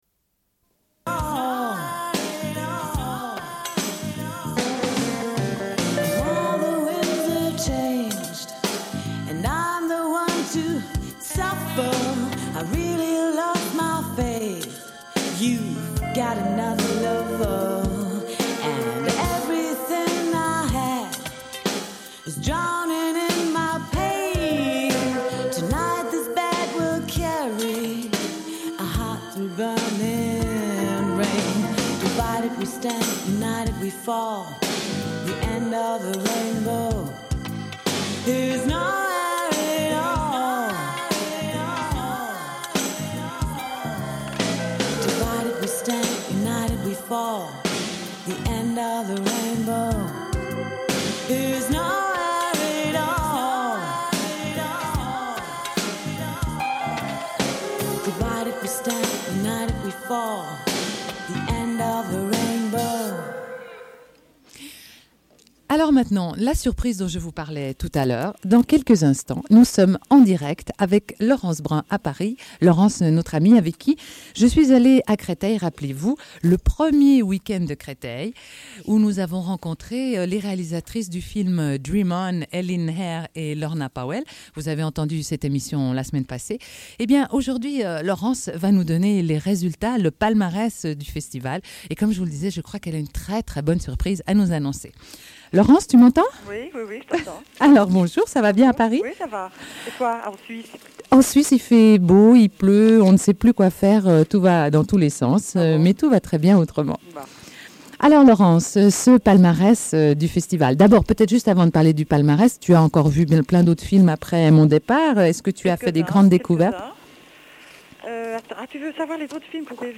Une cassette audio, face A00:31:26